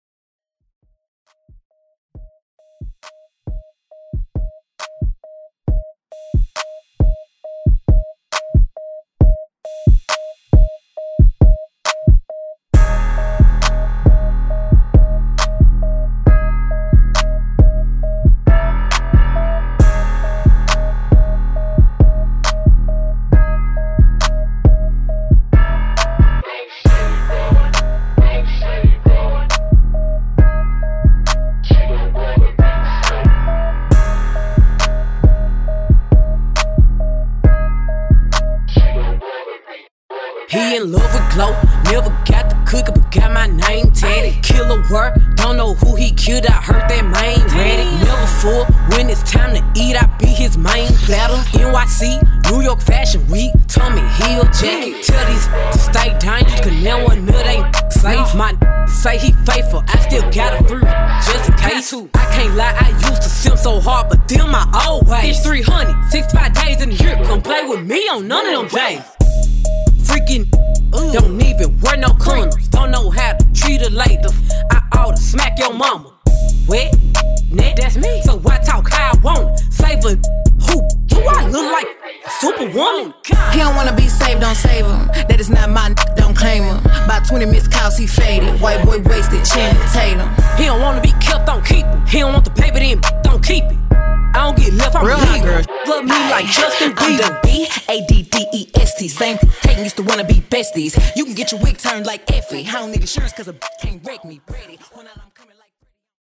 Genre: HIPHOP Version: Clean BPM: 68 Time